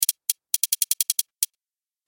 دانلود آهنگ تایپ 10 از افکت صوتی اشیاء
جلوه های صوتی
دانلود صدای تایپ 10 از ساعد نیوز با لینک مستقیم و کیفیت بالا